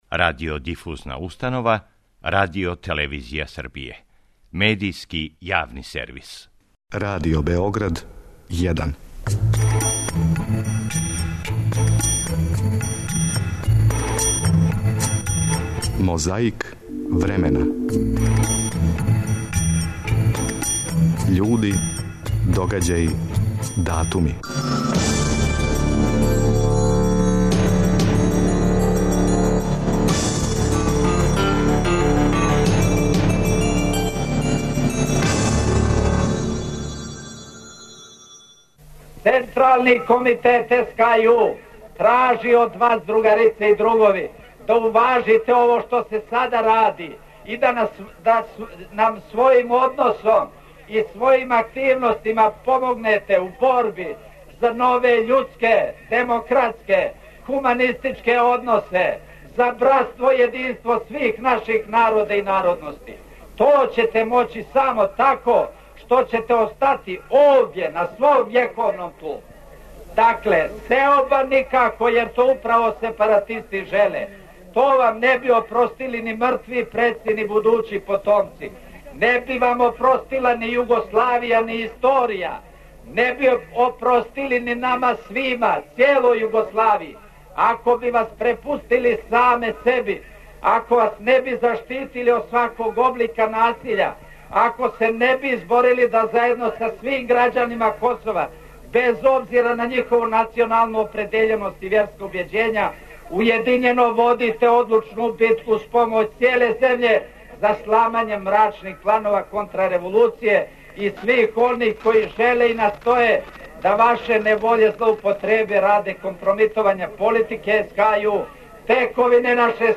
На београдском Тргу Маркса и Енгелса 20. октобра 1963. године, одржан је велики народни митинг поводом завршетка радова на ауто путу Братство-јединство. У таквим приликама, у то време, несебично се скандирало, аплаудирало, певало и спонтано радовало.
Другови и другарице слушаоци, чућете шта је том приликом рекао друг Александар Лека Ранковић.
Помажу нам снимци из Тонског архива Радио Београда.